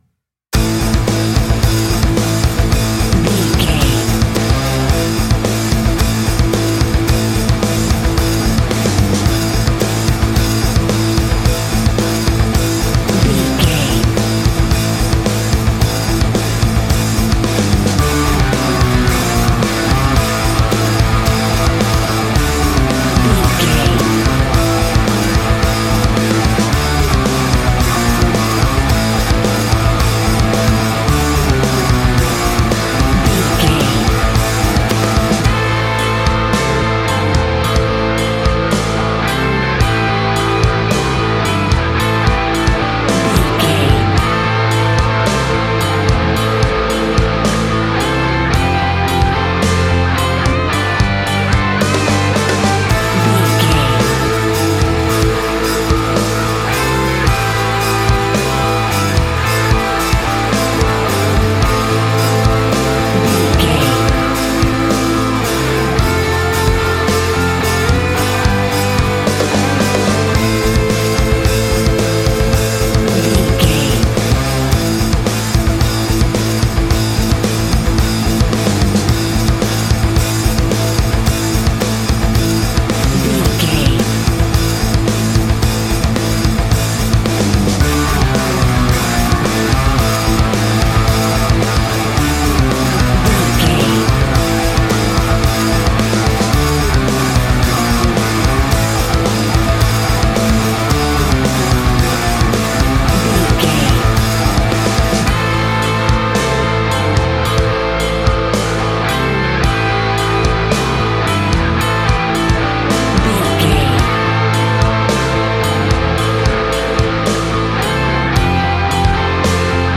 Aeolian/Minor
hard
groovy
powerful
electric guitar
bass guitar
drums
organ